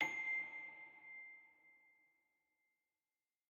celesta1_12.ogg